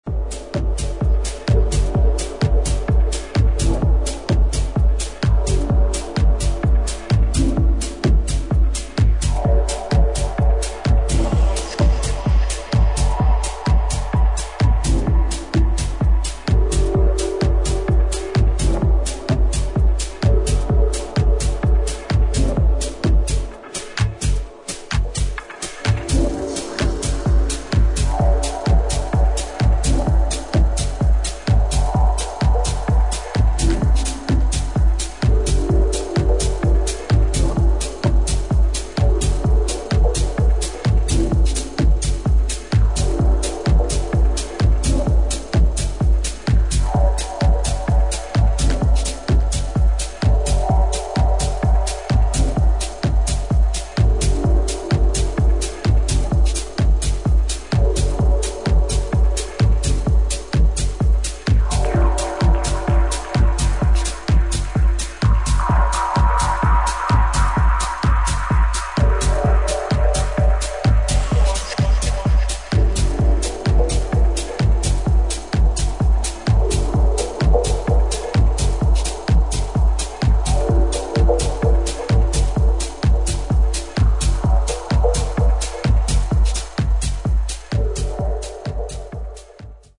シンプルながらも力強い、テッキーな硬い質感を保ったリズムセクションとクールなベースラインが特徴的である